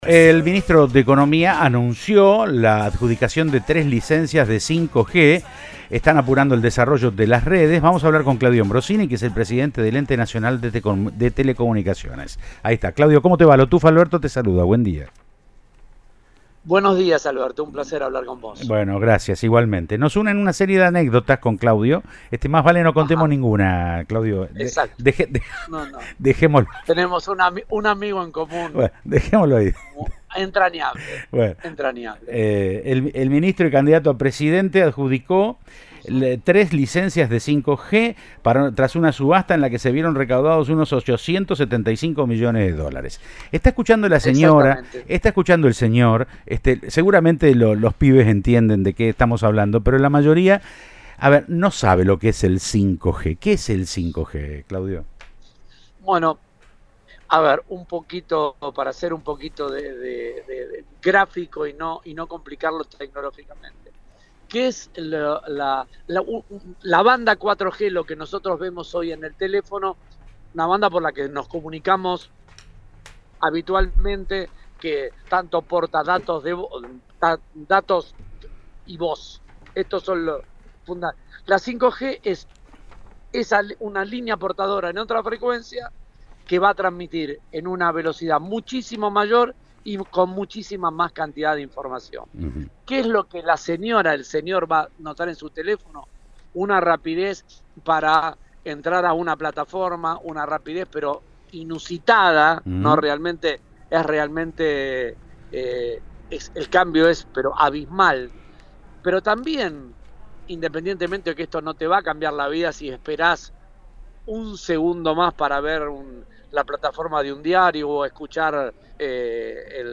Claudio Ambrosini, Presidente del Ente Nacional de Comunicaciones, se refirió a la subasta y los beneficios que traerá la tecnología.